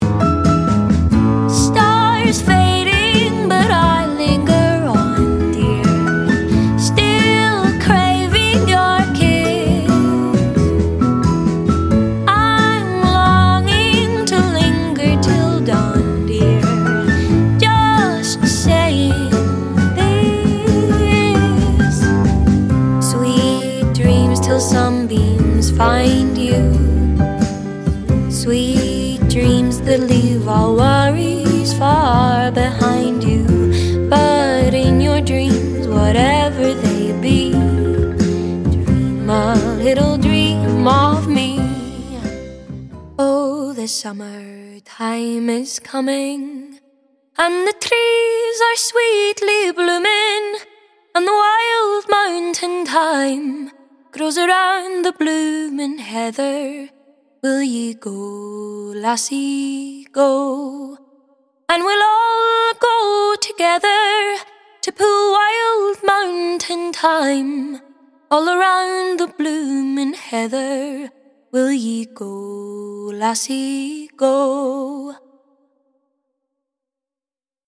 Singing Reel
Singing, Versatile, Kids Animation